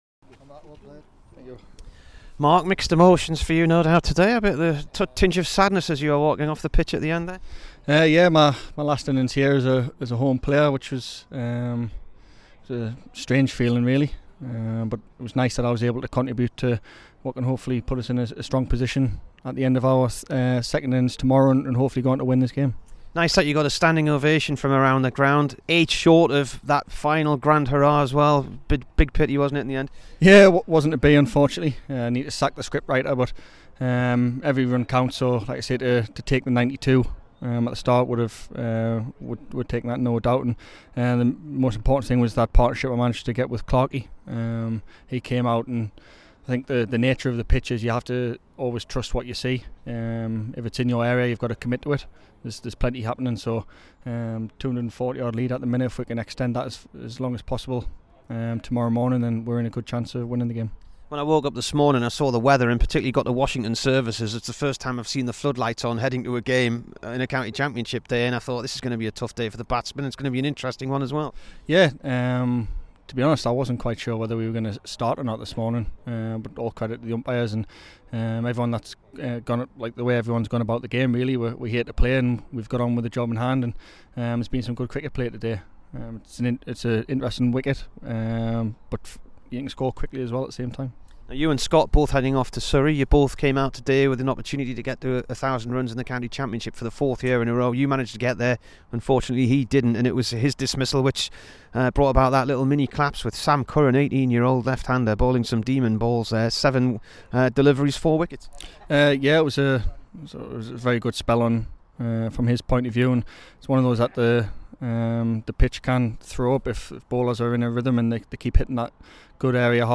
HERE'S THE DURHAM BATSMAN AFTER HIS FINAL HOME INNINGS FOR THE CLUB IN WHICH HE MADE 92 AGAINST SURREY.